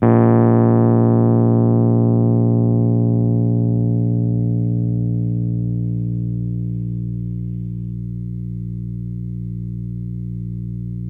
RHODES CL02R.wav